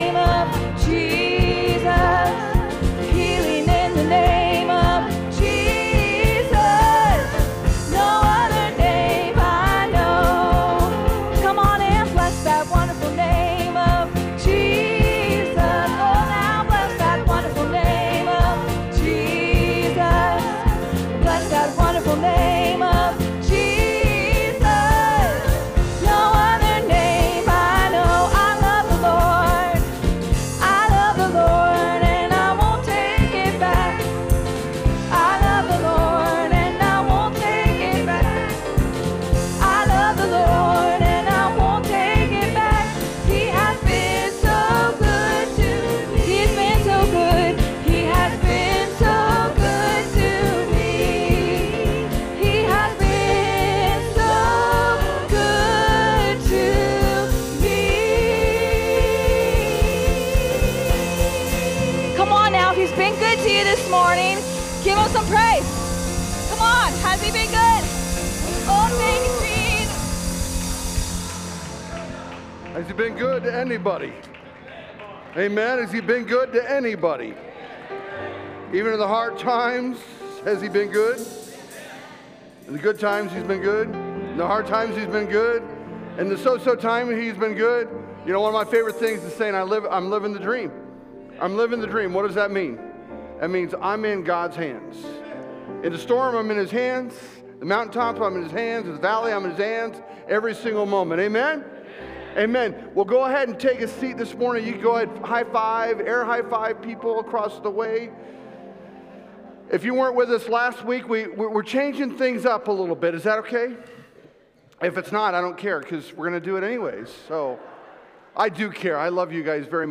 Sunday Morning Worship - Not your father's worship - Week 2